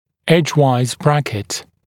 [‘eʤwaɪz ‘brækɪt][‘эджуайз ‘брэкит]брекет типа эджуайз